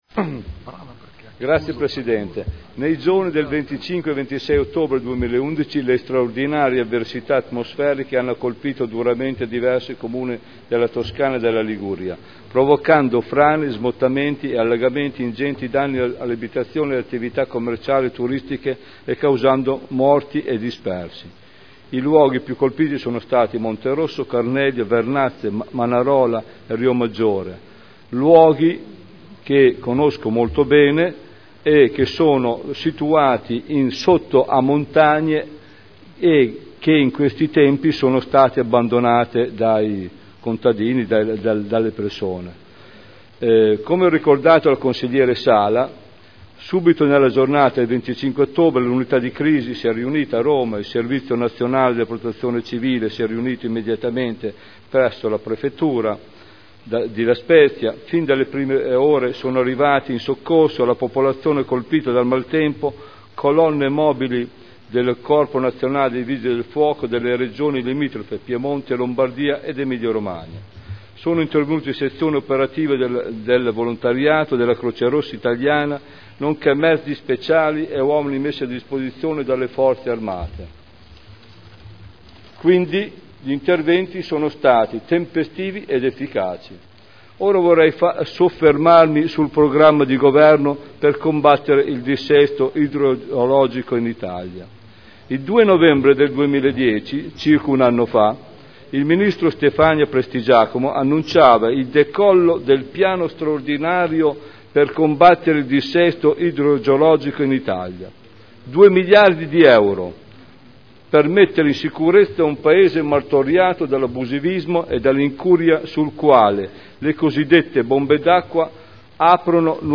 Stefano Goldoni — Sito Audio Consiglio Comunale